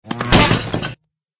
AA_drop_flowerpot_miss.ogg